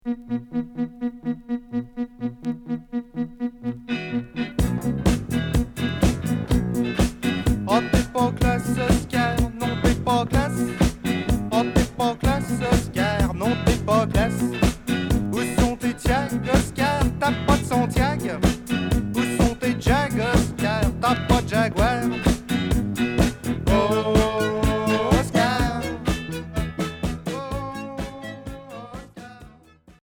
Rock new wave Unique 45t retour à l'accueil